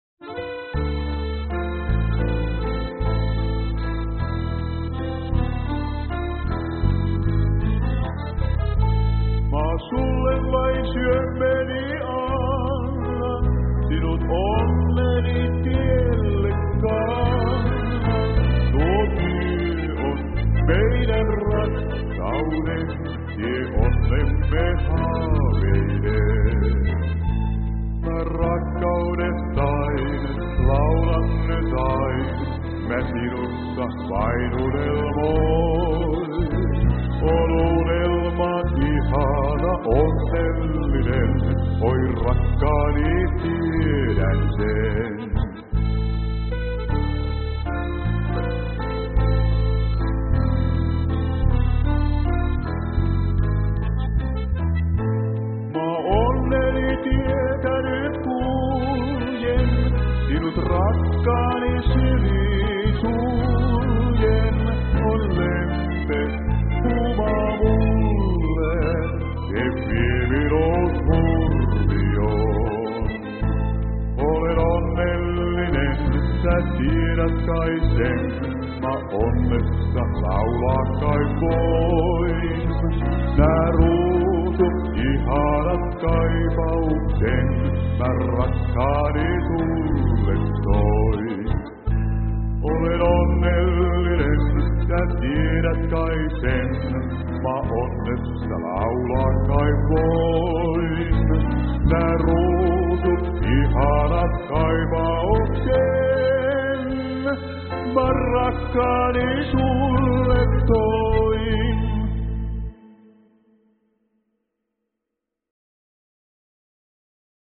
Pianossa